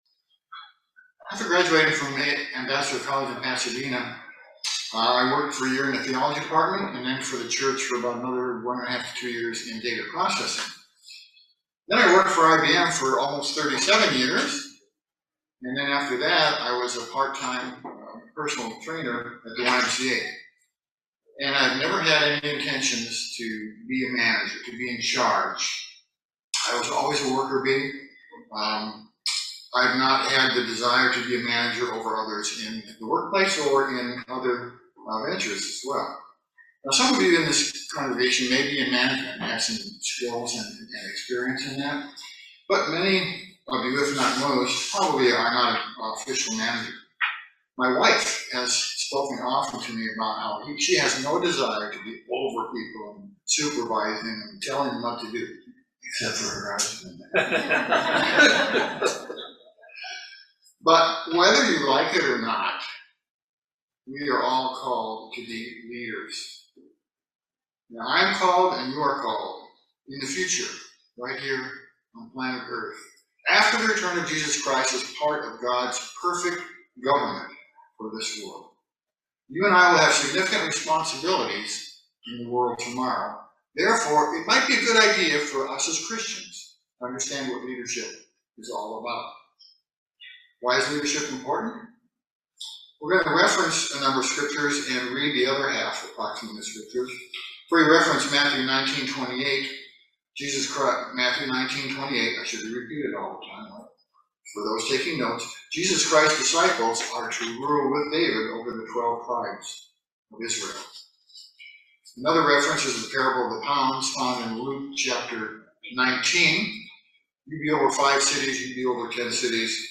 This video Sermon examines the subject of Servant Leadership. We are called to be leaders in God's coming government, and this sermon helps us to exam what God expects from us.
Given in Lexington, KY